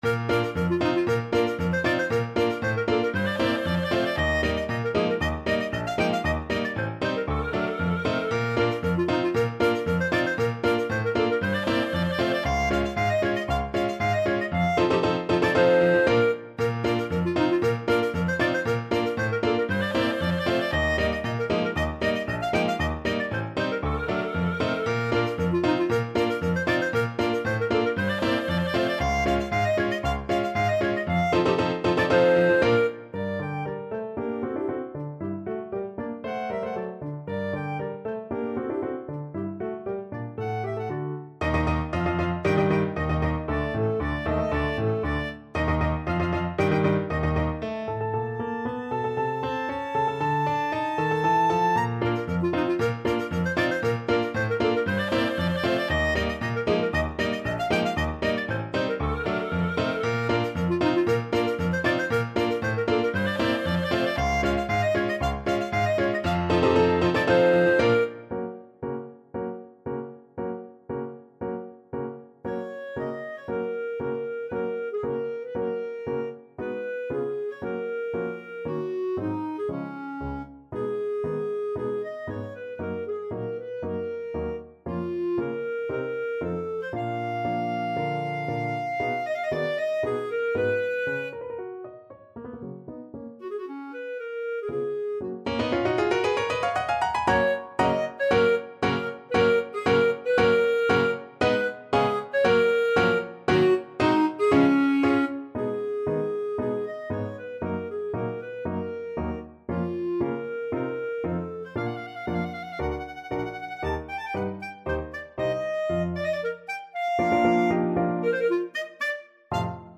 Clarinet
2/4 (View more 2/4 Music)
Bb major (Sounding Pitch) C major (Clarinet in Bb) (View more Bb major Music for Clarinet )
Allegro giocoso =116 (View more music marked Allegro giocoso)
Classical (View more Classical Clarinet Music)